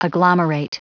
Prononciation du mot agglomerate en anglais (fichier audio)
Prononciation du mot : agglomerate